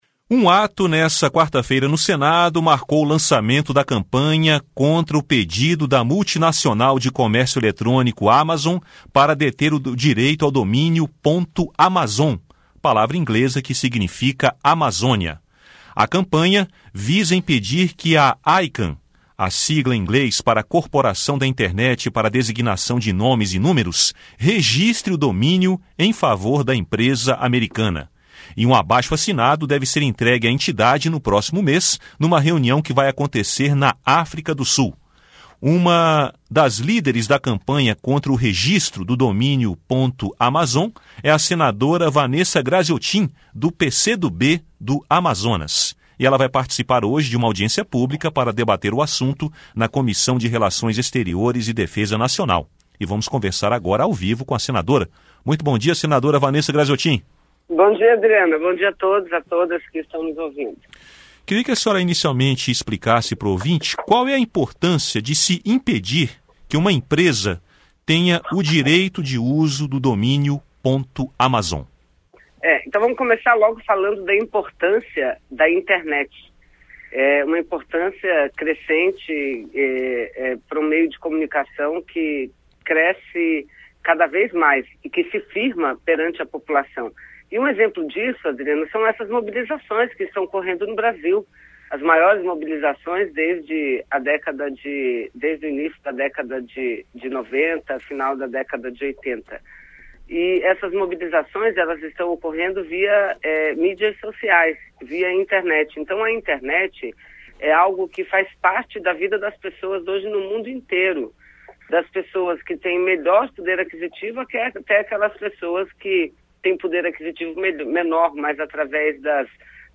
Entrevista com a senadora Vanessa Grazziotin (PCdoB-AM).